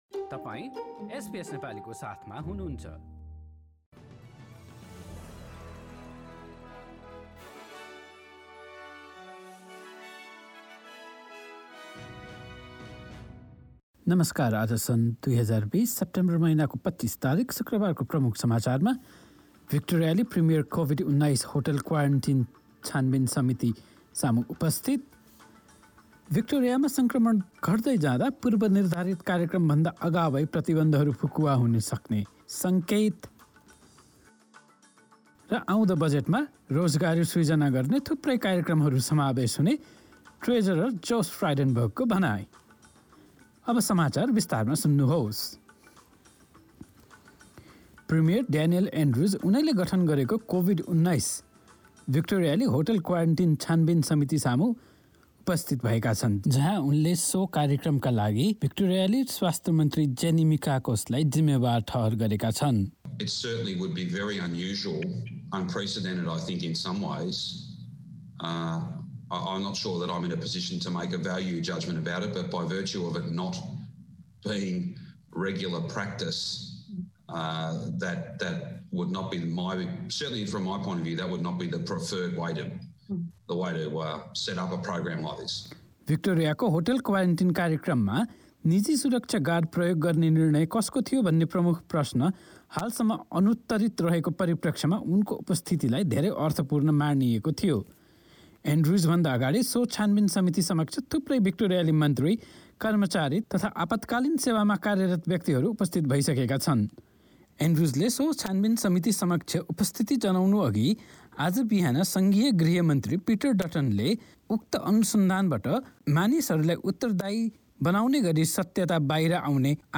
Listen to the latest news headlines in Australia from SBS Nepali radio - in this bulletin: Victoria's Premier Daniel Andrews faced the hotel quarantine inquiry Victoria's reopening may be fast-tracked as cases plummet... Treasurer Josh Frydenberg says the Federal Budget will contain a number of measures designed to boost employment.